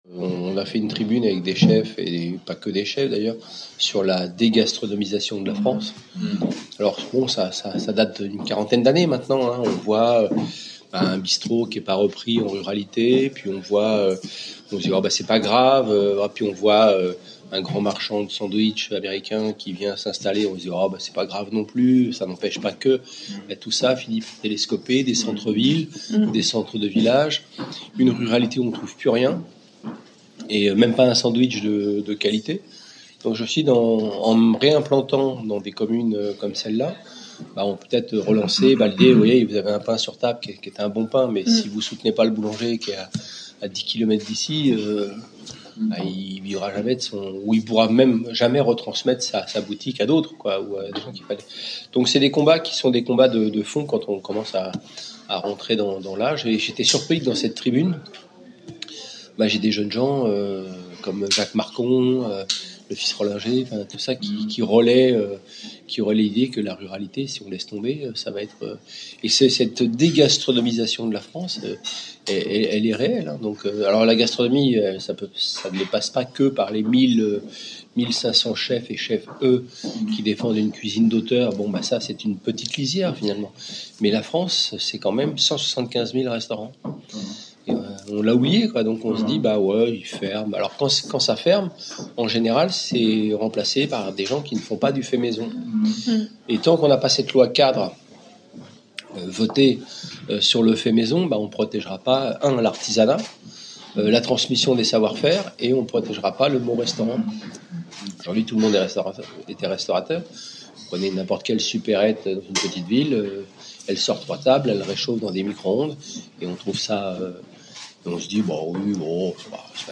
LES INTERVIEWS HAPPY RADIO – THIERRY MARX
les-interviews-happy-radio-thierry-marx